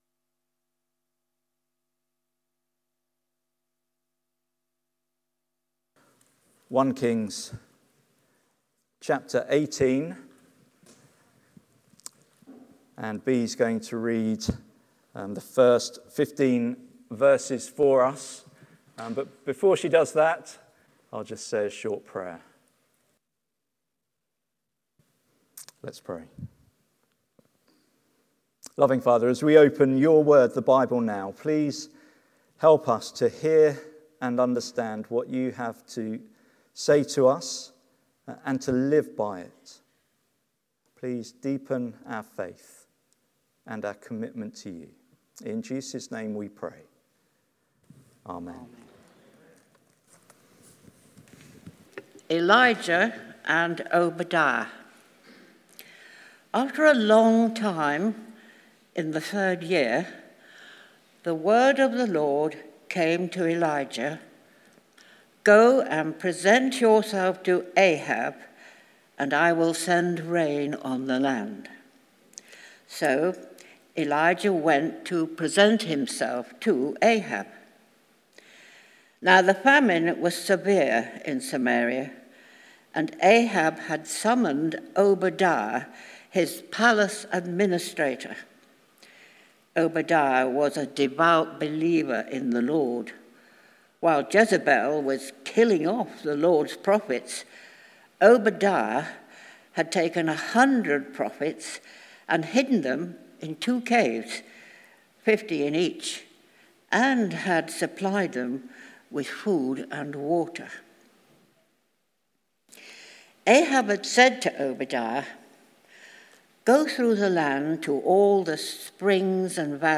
Exposition of 1 Kings Passage: 1 Kings 18:1-15 Service Type: Sunday Morning Sermon Transcript « Whose word will you trust?